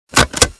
agraffeuse